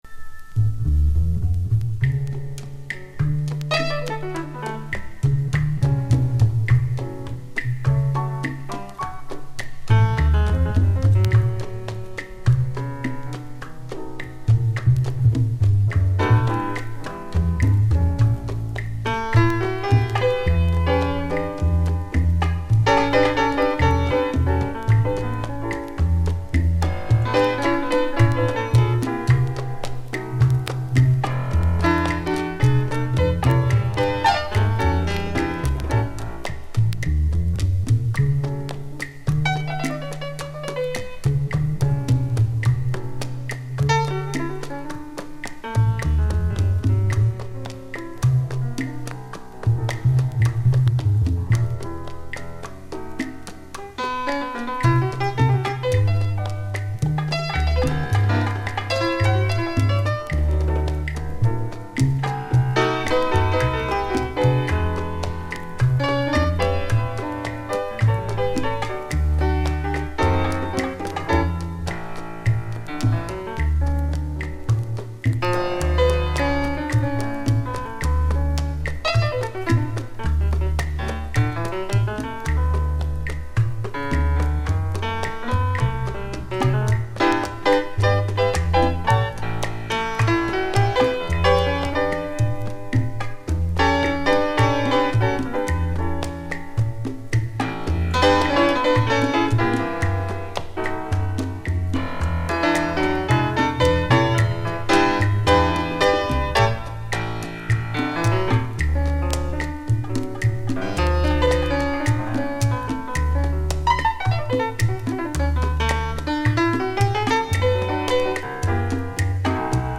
１９３０年代から活躍するキューバの超名門チャランガ・オルケスタの作品 !!!
ベースが太く